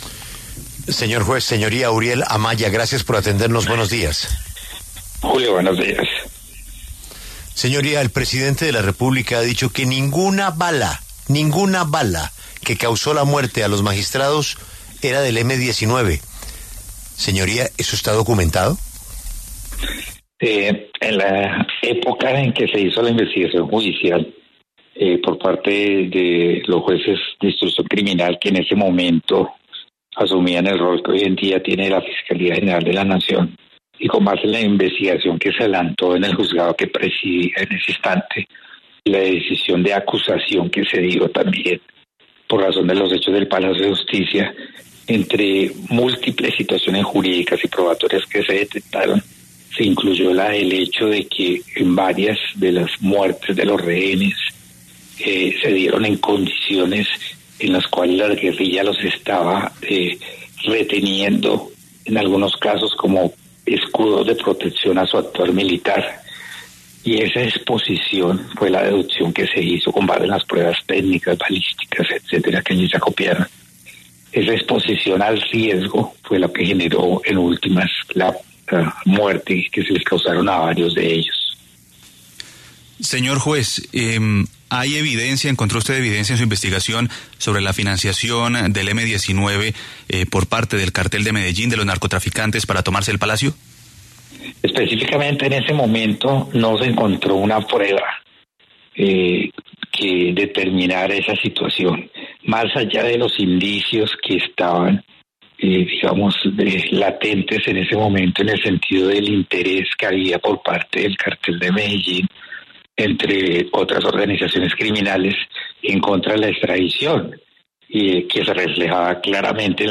En diálogo con La W, el juez Uriel Amaya, se refirió además al uso político que se le ha querido dar a la verdad de la toma y retoma del Palacio.